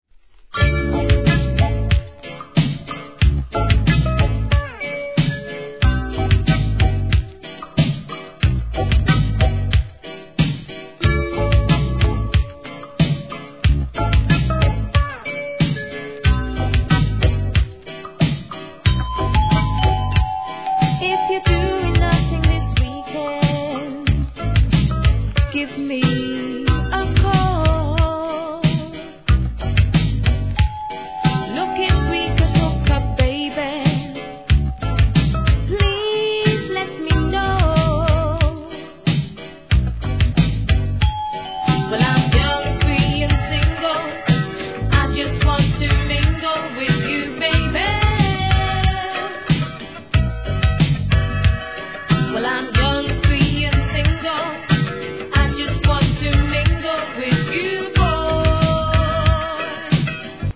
REGGAE
打ち込みでのソウル・カヴァー!